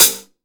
Index of /90_sSampleCDs/AKAI S-Series CD-ROM Sound Library VOL-3/DRY KIT#3
K-ZIL HH 1.wav